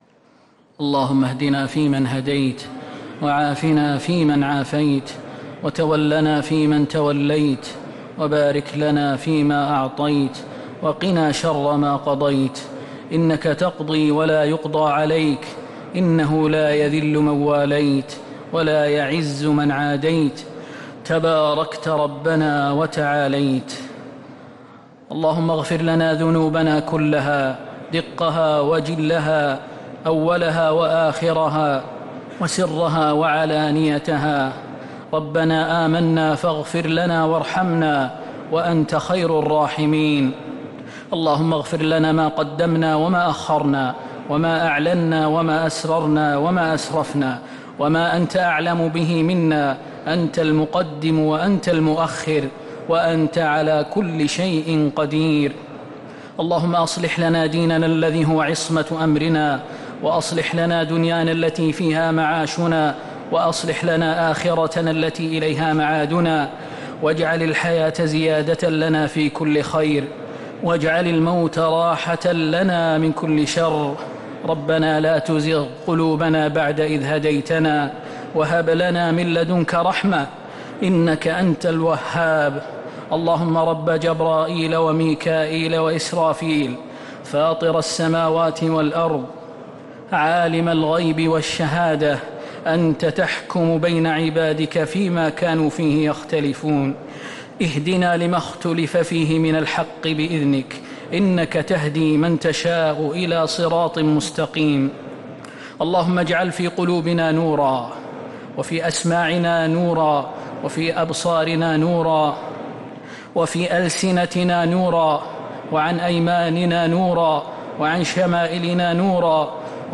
دعاء القنوت ليلة 19 رمضان 1447هـ | Dua 19th night Ramadan 1447H > تراويح الحرم النبوي عام 1447 🕌 > التراويح - تلاوات الحرمين